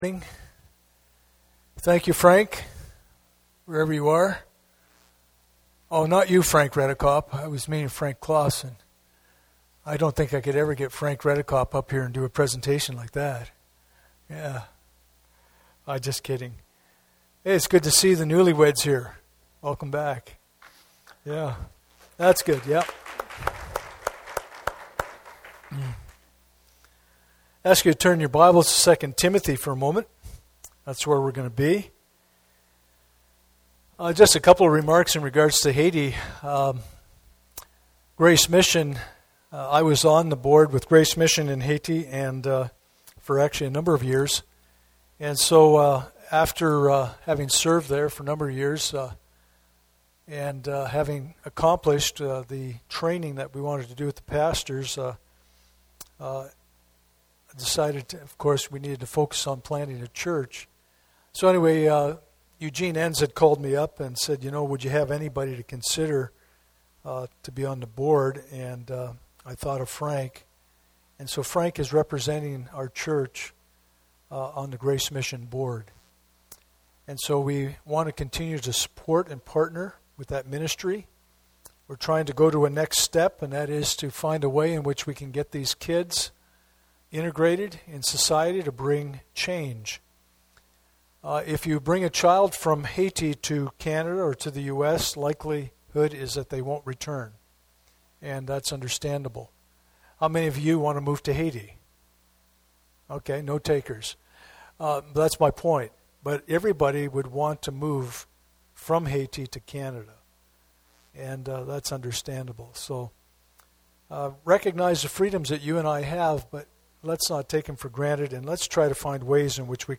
Pastoral Epistles Passage: 2 Timothy 2:16-20 Service Type: Sunday Morning « ABF